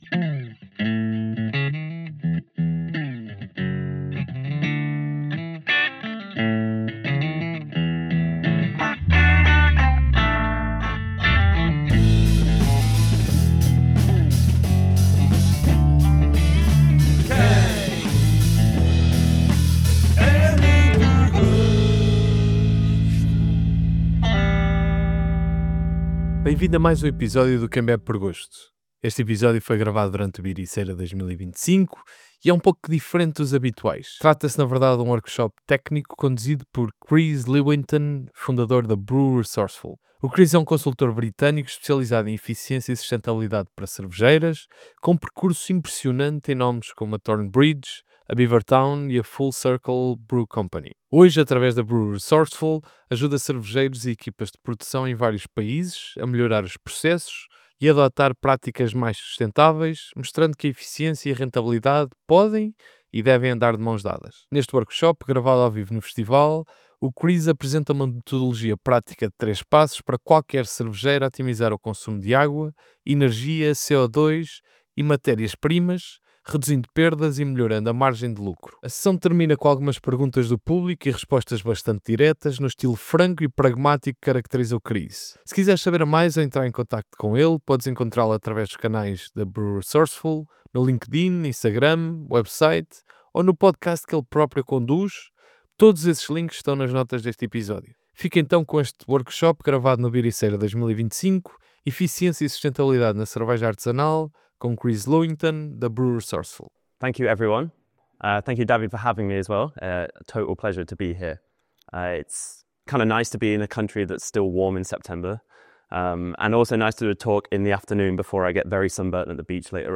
Como Poupar Água, Energia e Dinheiro na Fábrica— Workshop
BEERiceira 2025